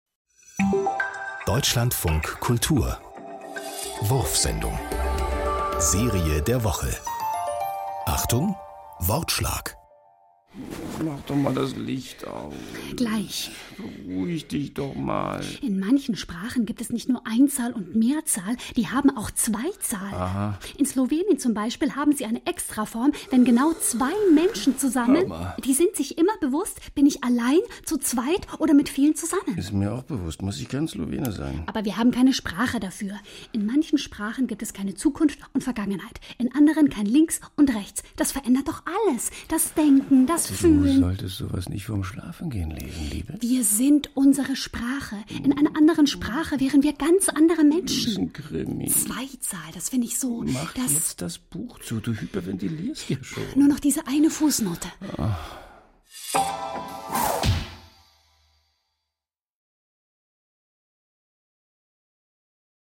Minihörspiele, die für abwegige Gedanken, neue Wahrnehmungen und intelligenten Humor werben.